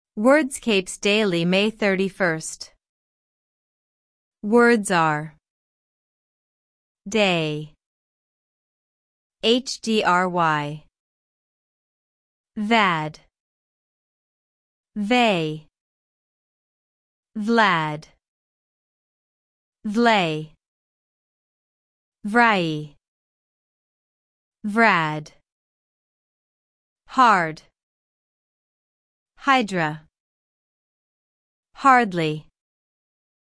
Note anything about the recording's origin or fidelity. On this page you’ll get the full Wordscapes Daily Puzzle for May 31 Answers placed in the crossword, all bonus words you can collect along the way, and an audio walkthrough that can read the answers to you at the speed you like while you’re still playing.